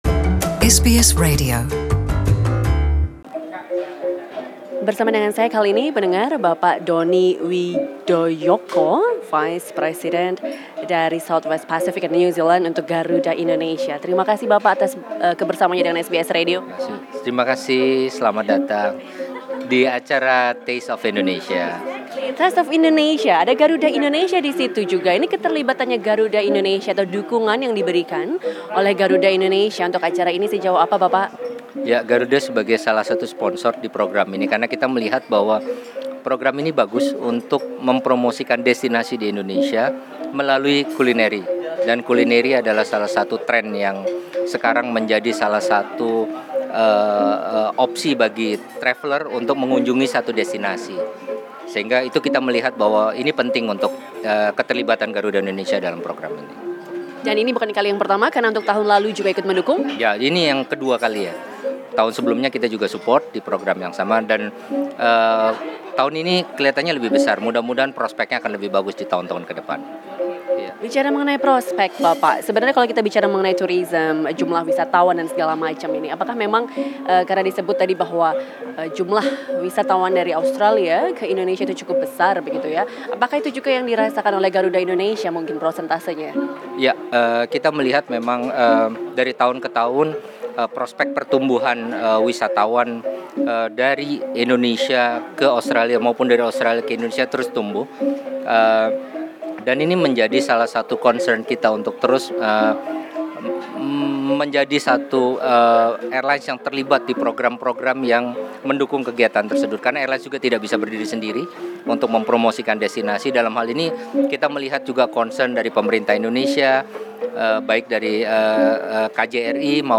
Met at the inaugural of the 'Taste of Indonesia' food festival at Shangri-La Hotel Sydney on Thursday (16/8/2018)